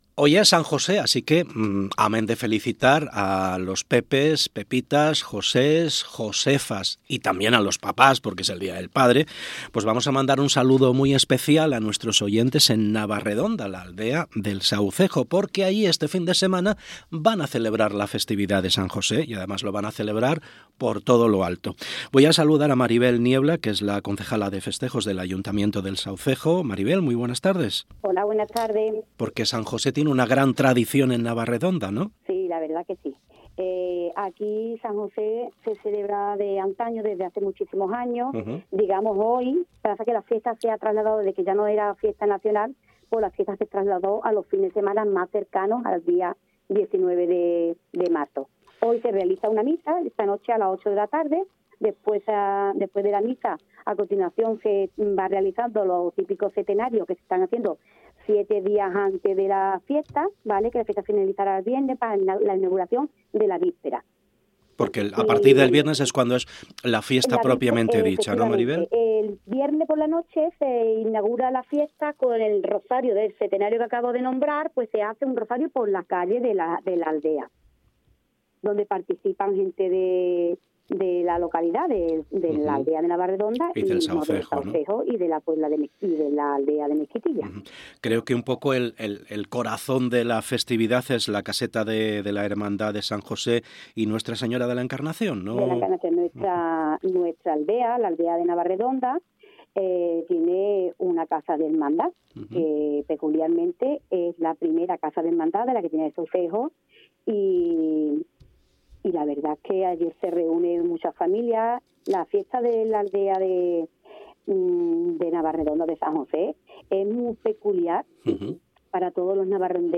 Entrevista Maribel Niebla. Fiestas de San José,